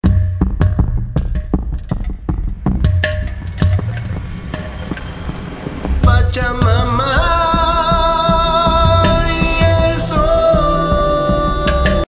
Hier wäre ein schöner Wooosh-Sound nicht schlecht.
Ich entscheide mich für das Preset Discreet Metal und baue noch eine gegenläufige Panorama-Bewegung der beiden Woosh-Layer ein.